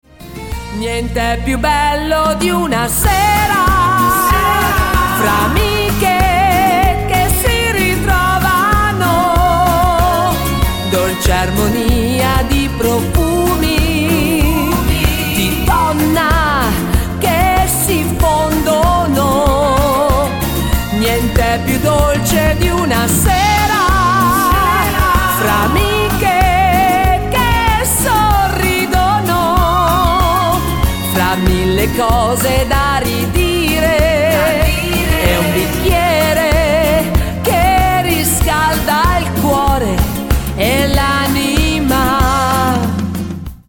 MODERATO  (3.48)